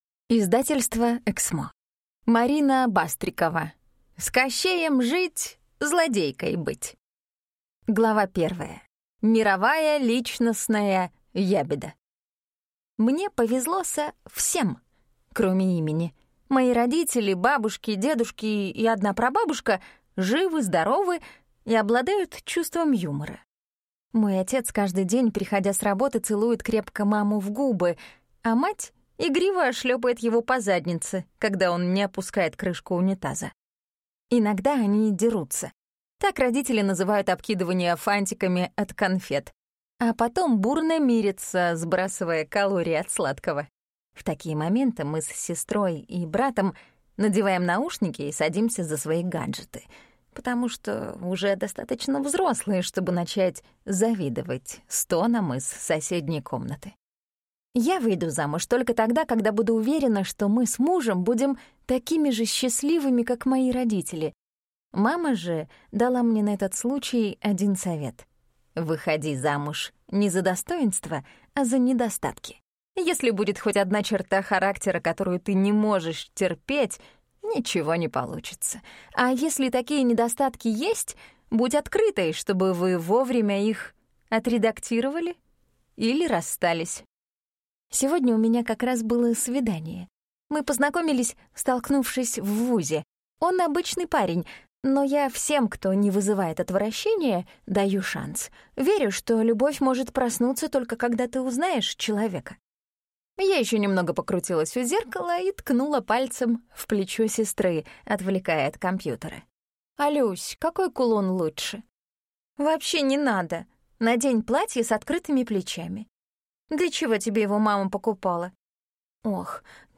Аудиокнига С Кощеем жить – злодейкой быть | Библиотека аудиокниг